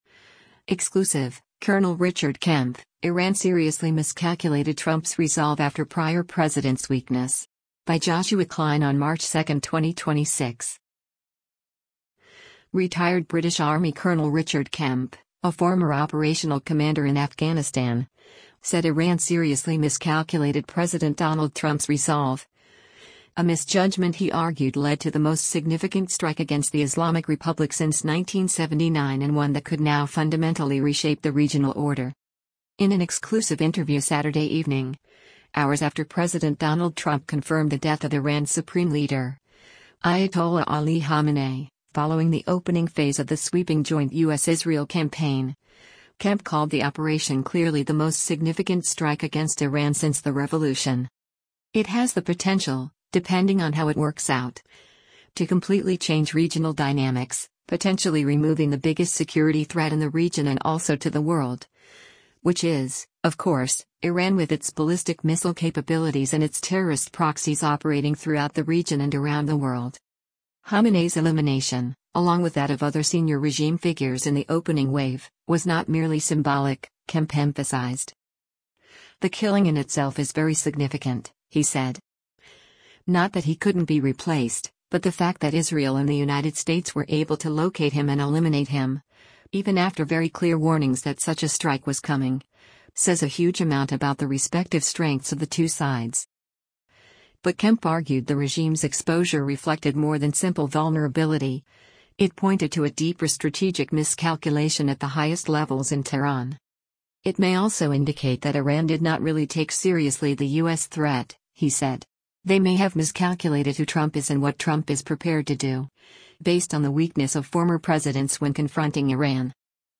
In an exclusive interview Saturday evening, hours after President Donald Trump confirmed the death of Iran’s Supreme Leader, Ayatollah Ali Khamenei, following the opening phase of the sweeping joint U.S.-Israel campaign, Kemp called the operation “clearly the most significant strike against Iran since the revolution.”